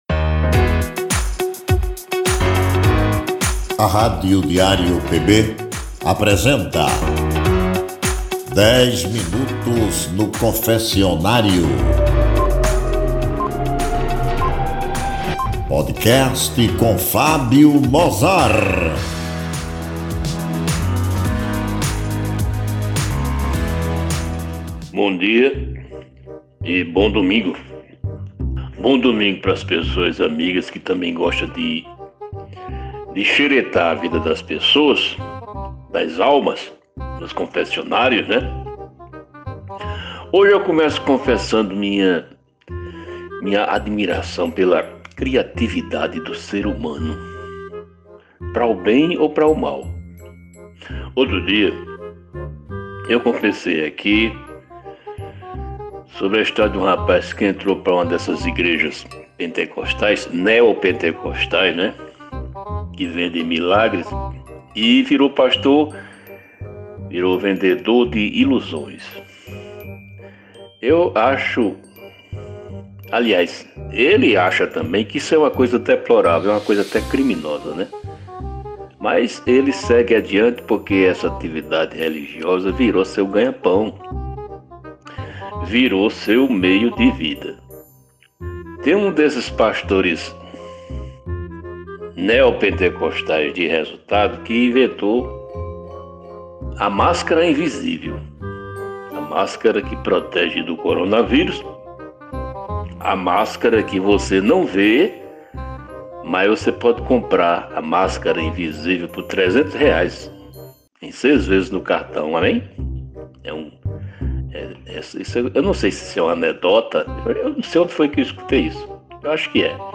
é um programa com um papo descontraído, às vezes incomum, sobre as trivialidades do nosso cotidiano.